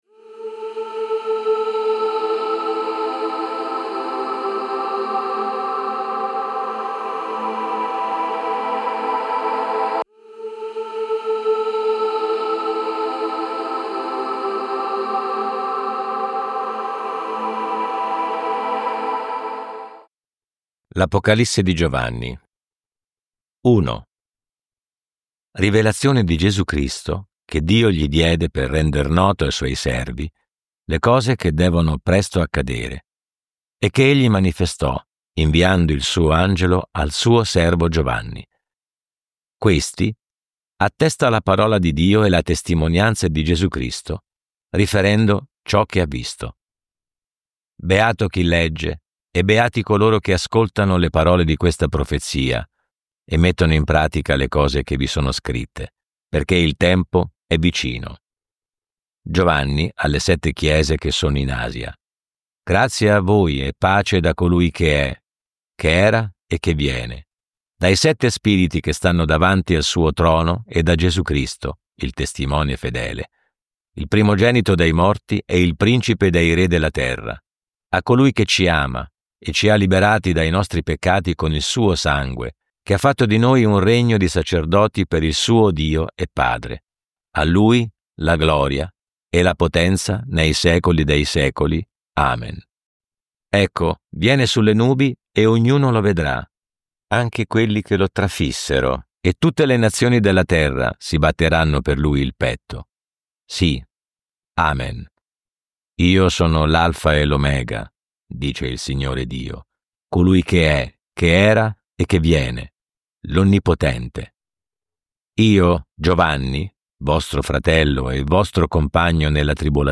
Audiolibri integrali, sempre gratis.